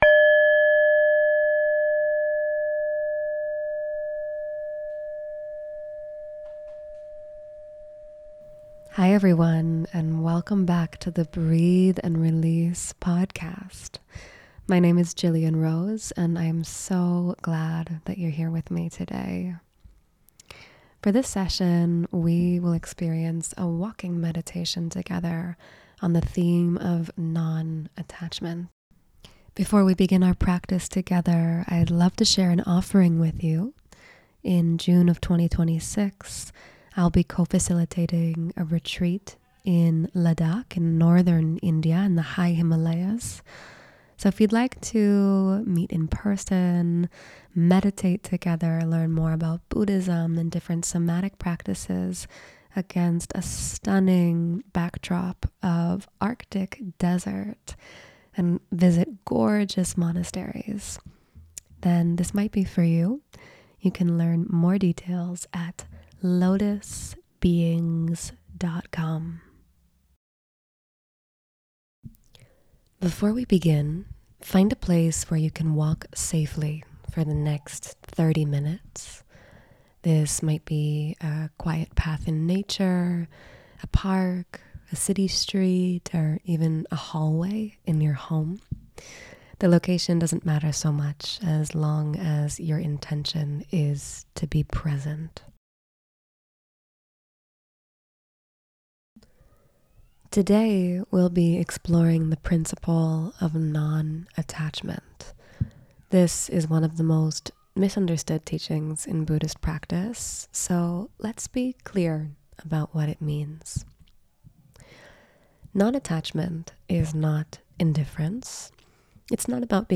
Through gentle guidance and mindful movement, you'll learn to release tension, let go of control, and walk with open hands and an open heart.